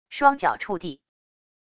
双脚触地wav音频